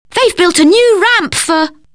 Dans le dialogue, un mot n'est pas audible.